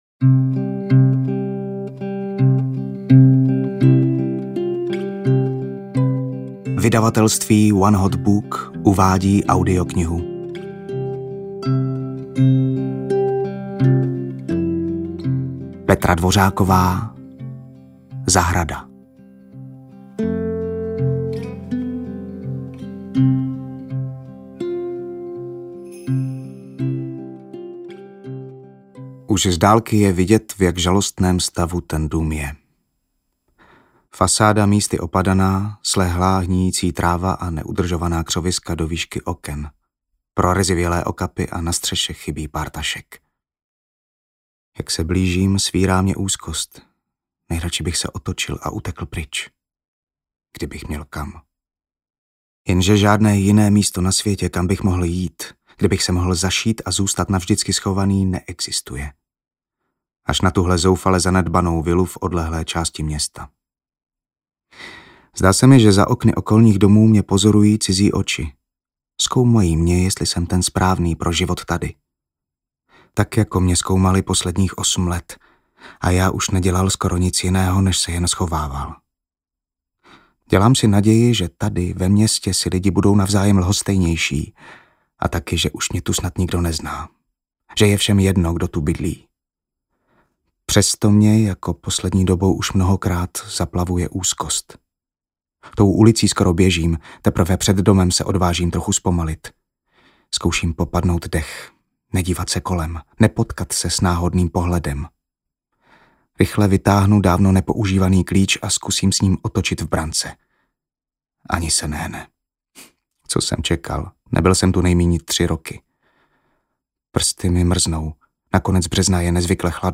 Zahrada audiokniha
Ukázka z knihy